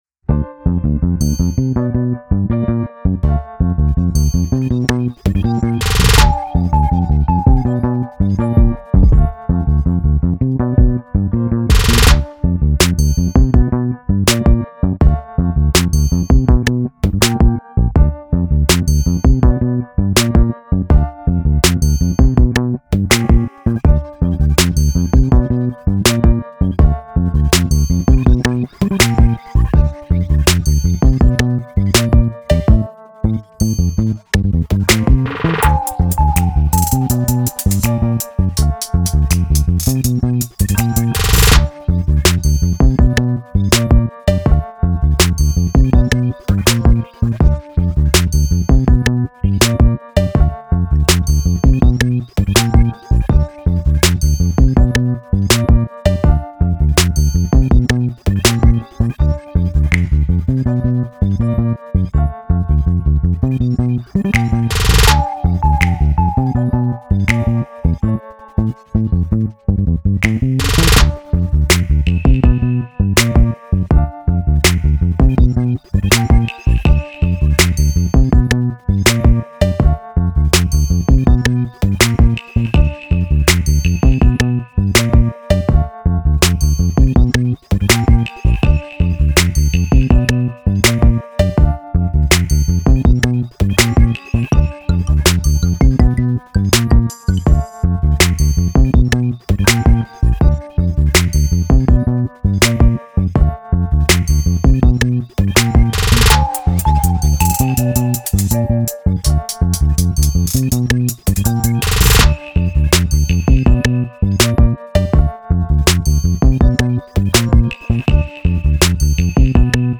フリーBGM